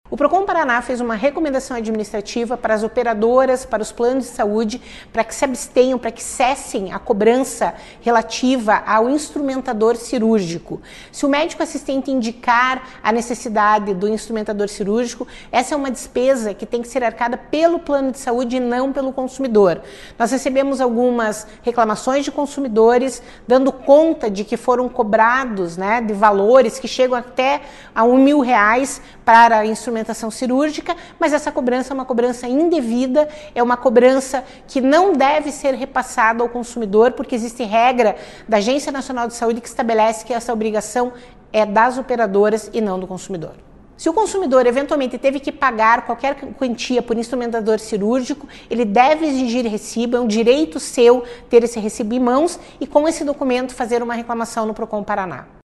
Sonora da coordenadora do Procon-PR, Claudia Silvano, sobre a cobrança indevida de taxa de instrumentador cirúrgico